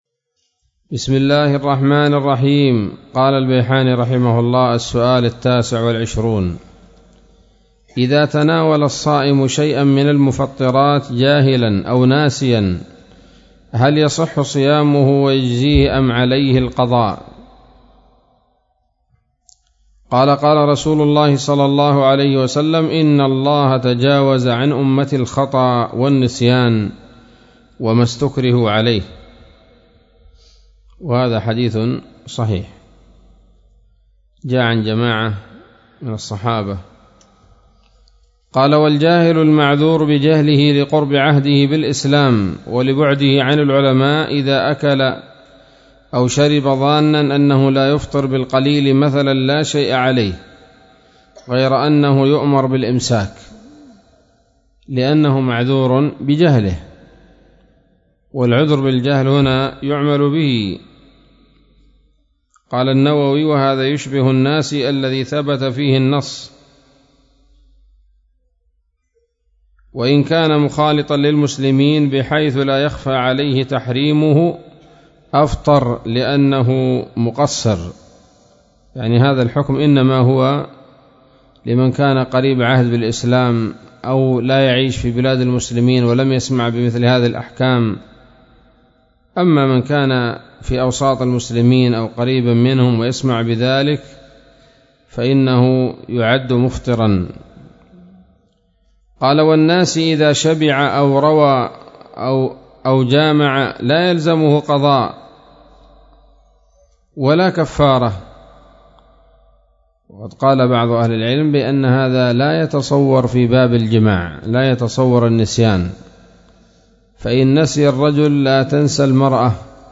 الدرس الثالث والعشرون من تحفة رمضان للعلامة البيحاني